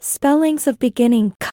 Spellings-Of-beginning-k-cat-kit-phoneme-name-AI.mp3